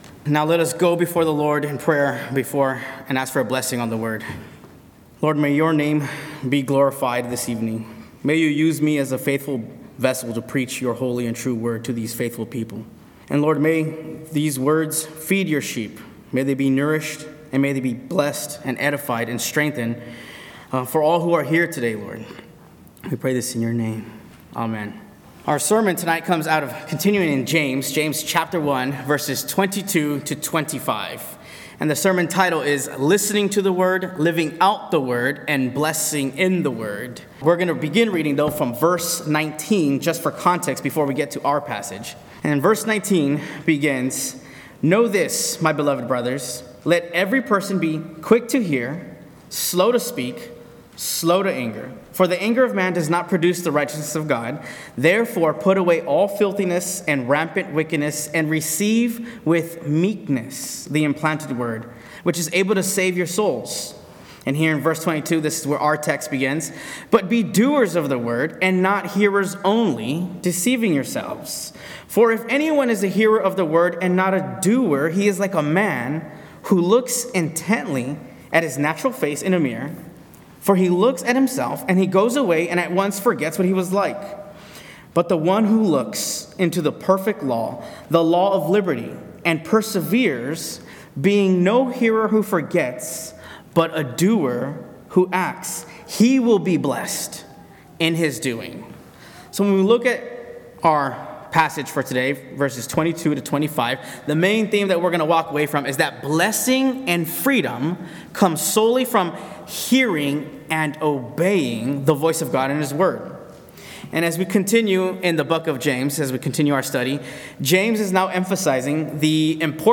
Word Series Various Sermons Book James Watch Listen Save In James 1:22-25, James stresses the importance of listening to God through His Word and being diligent to live according to His Word.